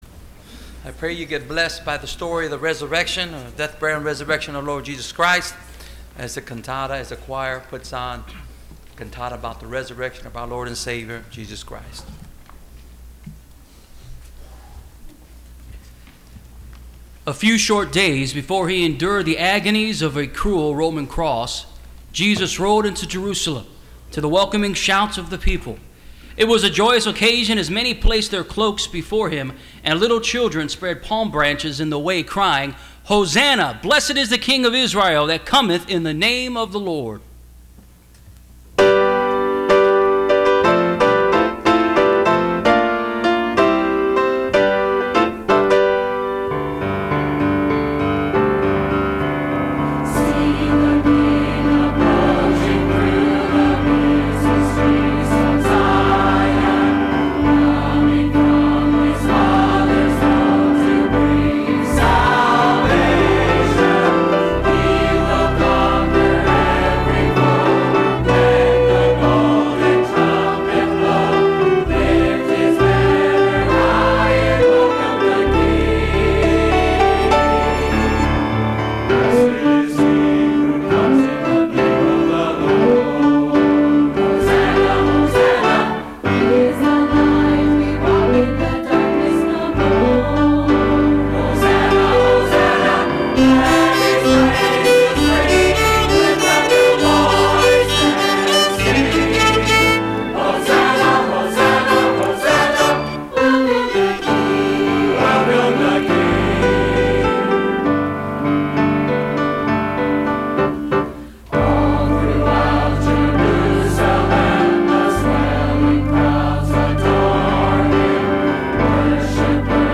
Service Type: Sunday Evening Choir